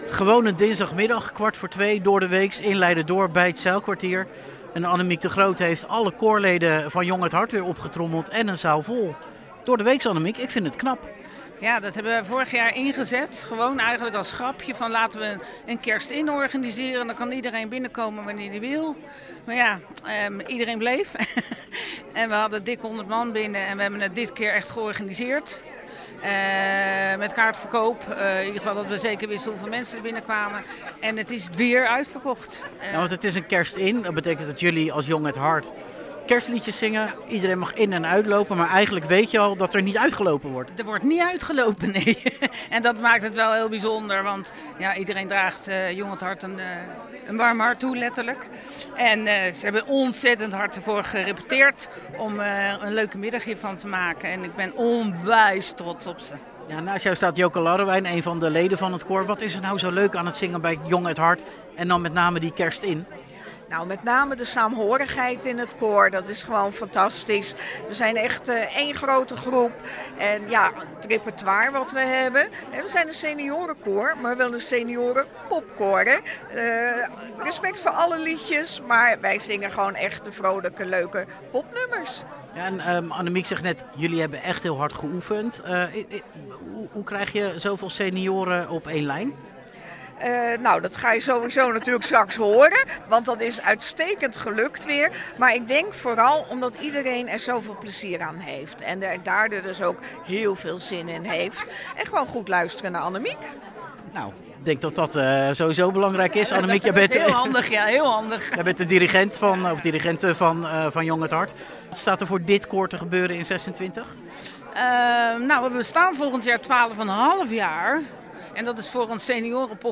koorleden